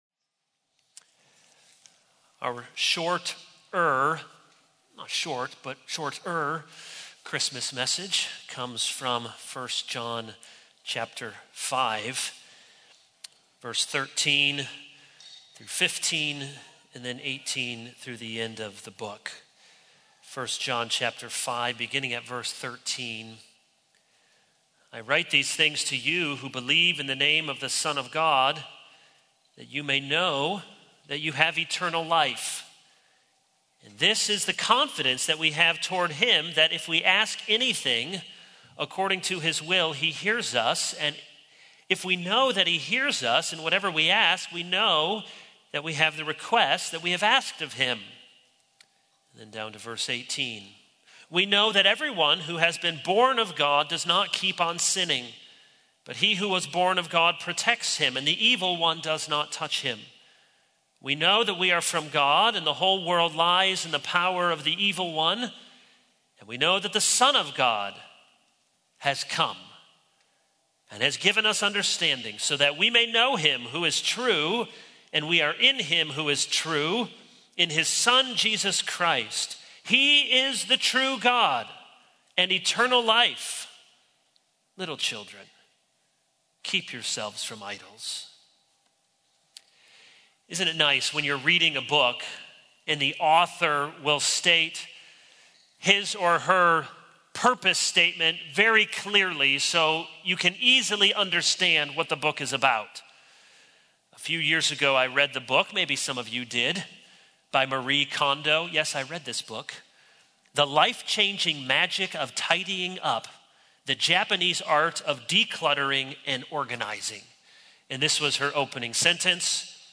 This is a sermon on 1 John 5:13-21.